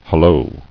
[hul·lo]